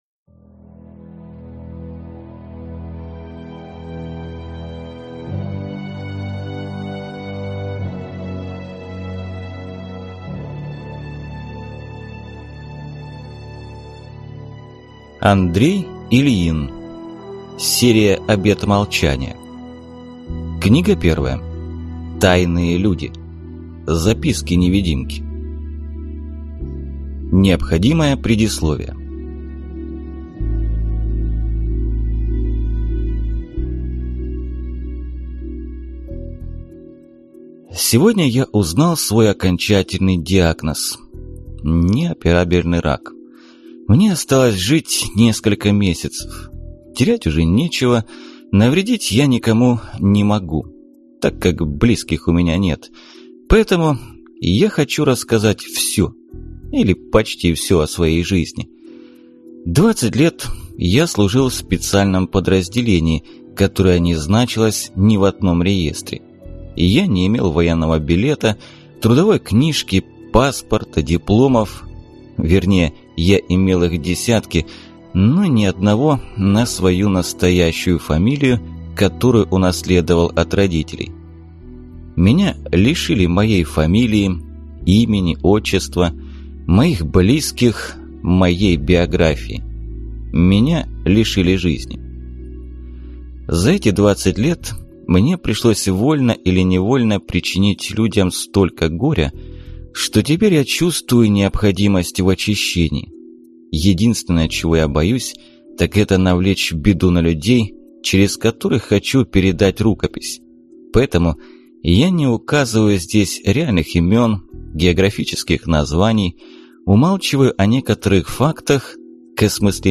Аудиокнига Обет молчания. Тайные Люди | Библиотека аудиокниг